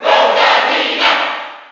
File:Rosalina & Luma Cheer NTSC SSB4.ogg
Rosalina_&_Luma_Cheer_NTSC_SSB4.ogg